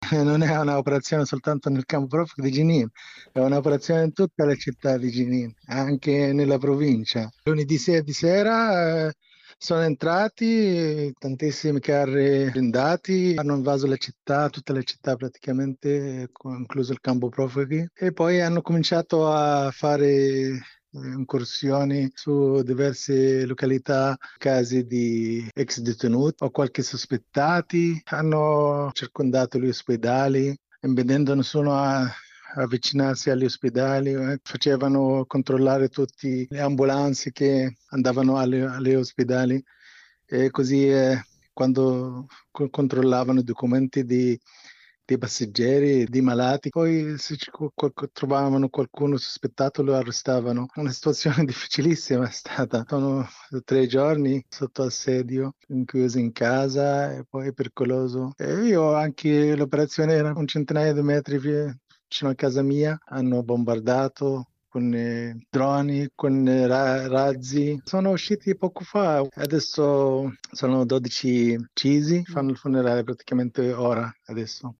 Il racconto della giornata di giovedì 14 dicembre 2023 con le notizie principali del giornale radio delle 19.30.
architetto palestinese.